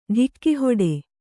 ♪ ḍhikki hoḍe